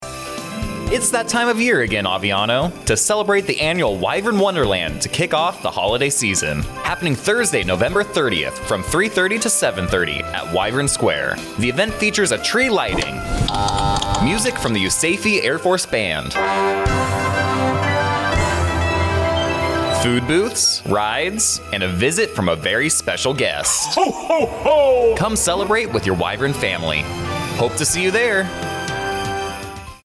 A radio spot for Wyvern Wonderland 2023 at Aviano Air Base. The event will feature a tree lighting ceremony, music from the USAFE Air Force Band, food booths, a visit from a special guest, and much more.